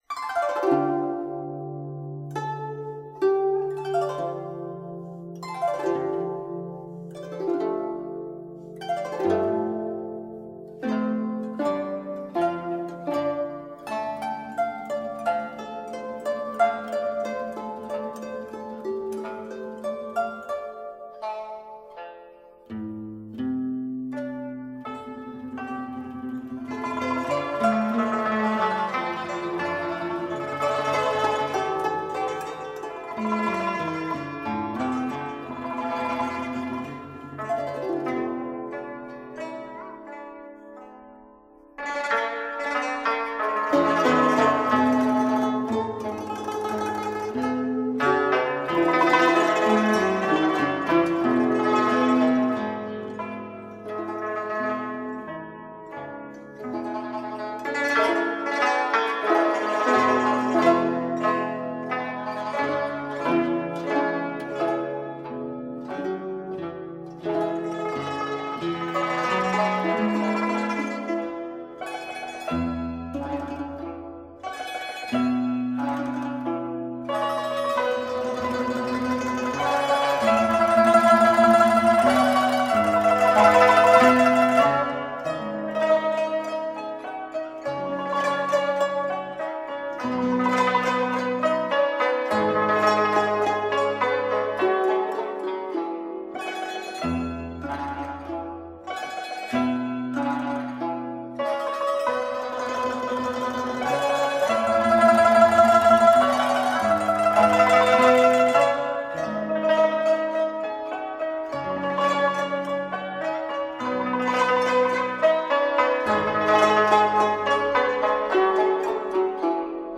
中国民族器乐中的花鸟侧重其情调和风格，风格幽默，
妙趣横生，音乐拟声表情，给人以更完善的艺术美感。
傣、维吾尔等民族的风情，以“写生”的笔触赋予丰富的色调，风格清新。
02.惜红衣　　　　　　　(民乐合奏)